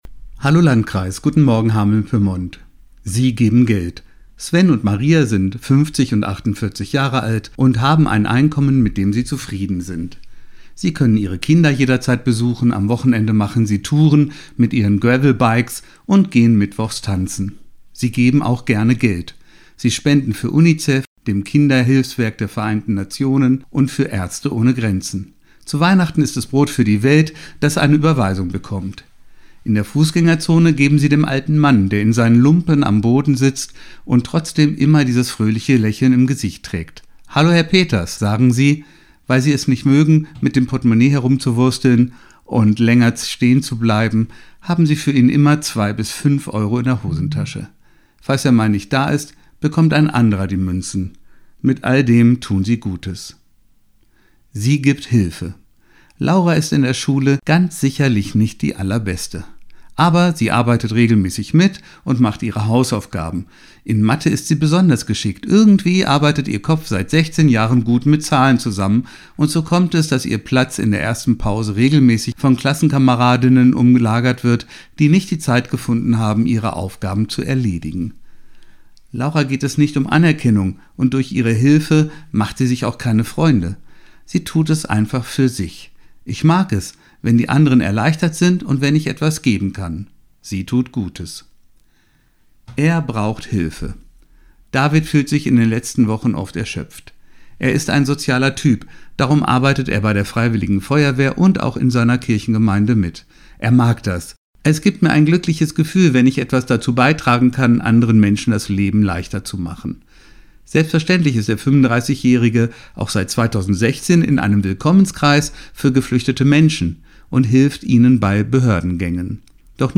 Radioandacht vom 26. Mai